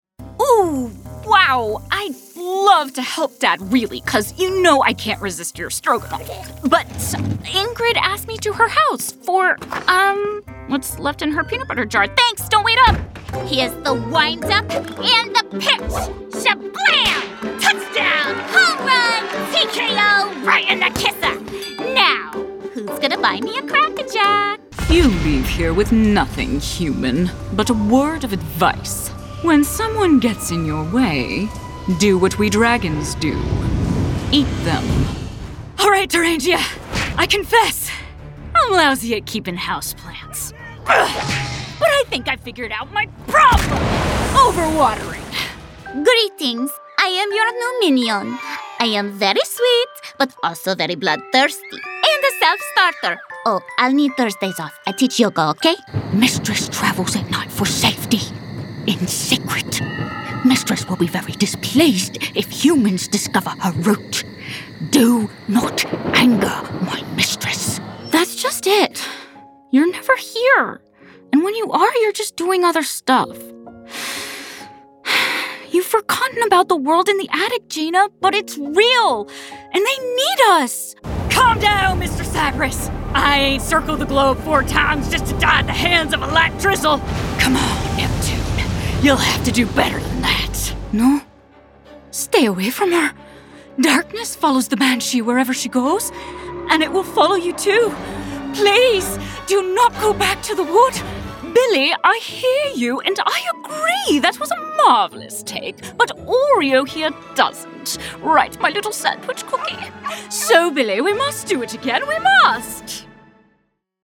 Teenager, Young Adult, Adult, Mature Adult
Has Own Studio
british rp | natural
standard us | natural
ANIMATION 🎬